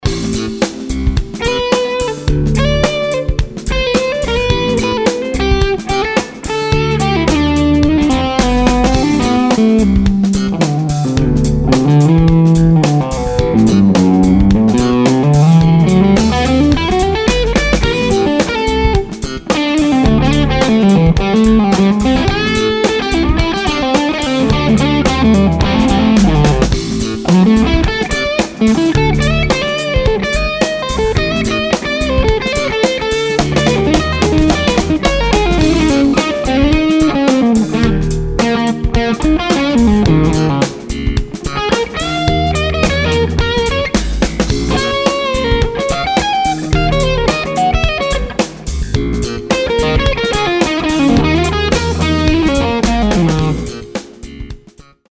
Try this other one, I added a touch of bass interstage. It seems a little brighter and more focused.
The sound is more focused and pleasant to my ears.